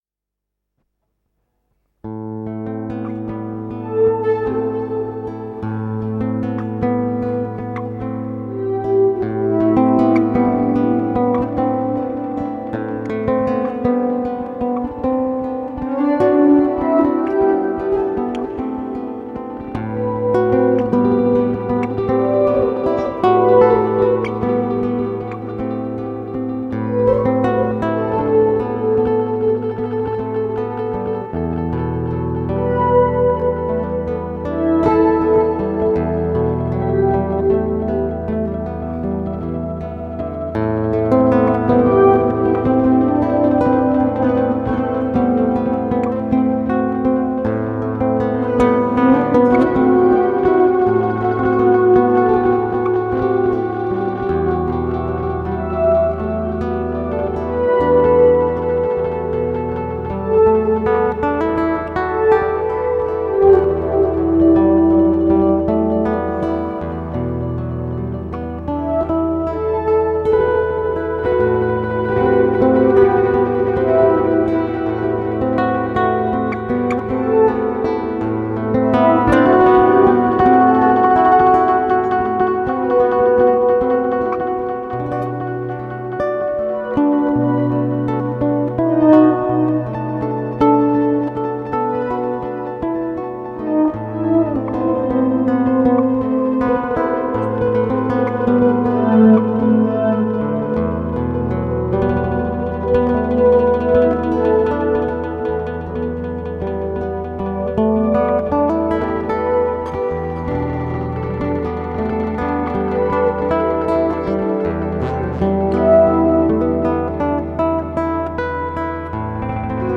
Gitarren